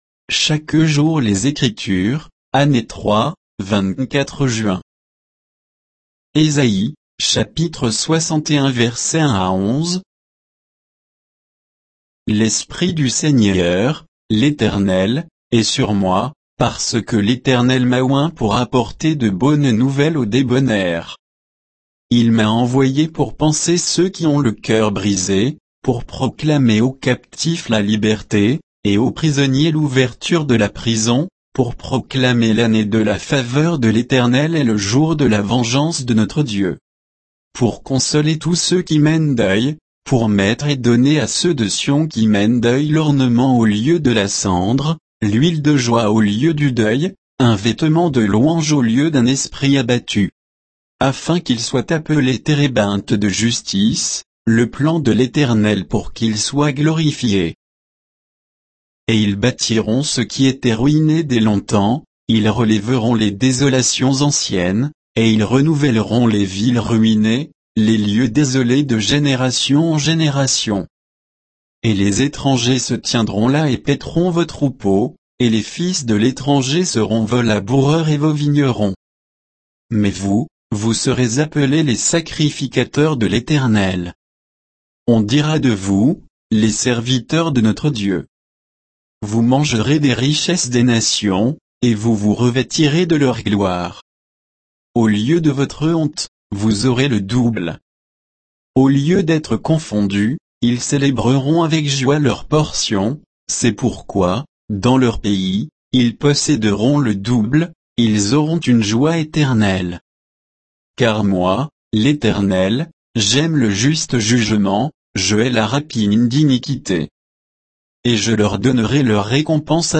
Méditation quoditienne de Chaque jour les Écritures sur Ésaïe 61, 1 à 11, par J.Koechlin